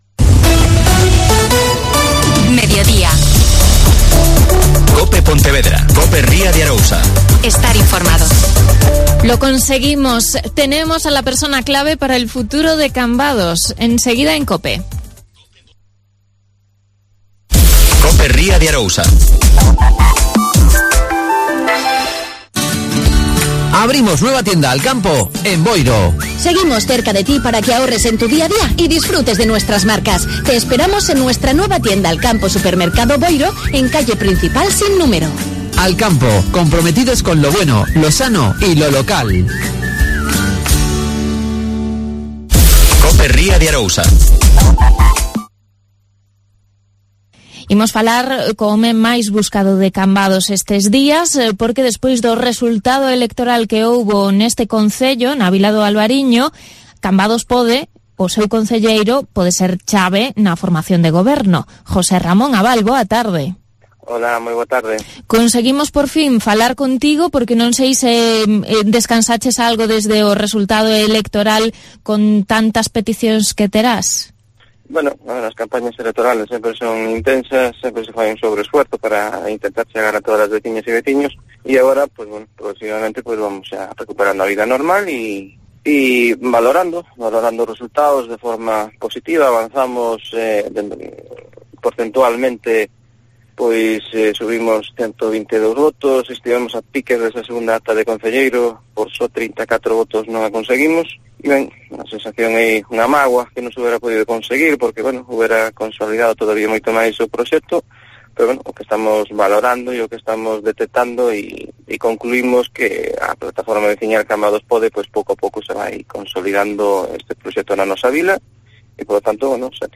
AUDIO: José Ramón Abal. Concejal de "CAMBADOS PODE".